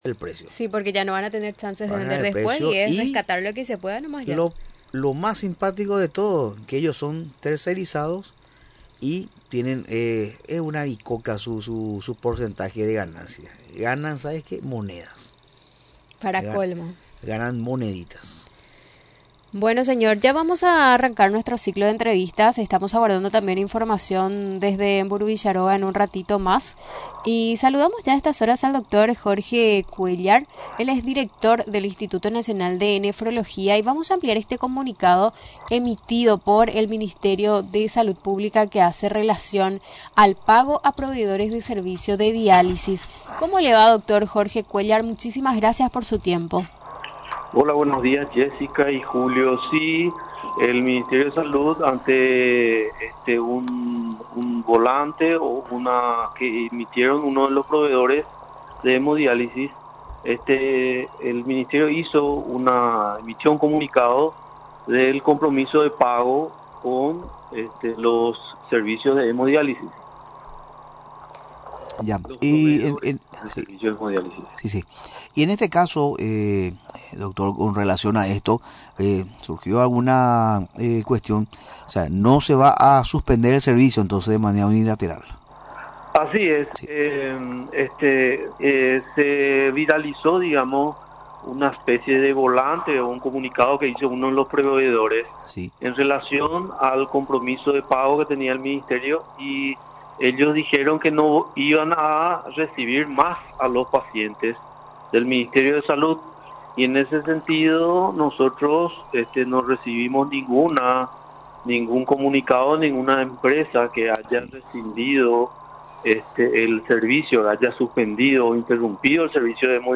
En entrevista en Radio Nacional del Paraguay, refrió que la nota de la cartera sanitaria, menciona que a la fecha ninguna empresa proveedora notificó la interrupción de sus servicios al Ministerio, como es su obligación conforme a la Ley de Contrataciones Públicas.